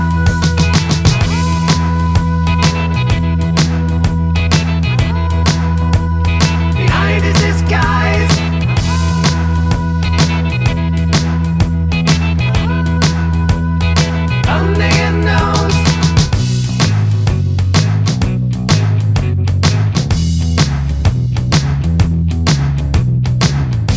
No Guitar Glam Rock 3:17 Buy £1.50